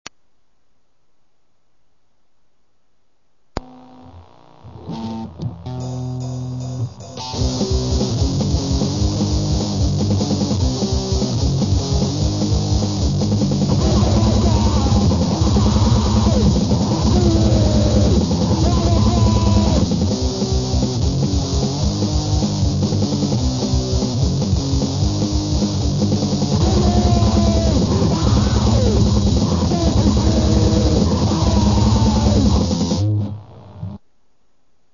Grind core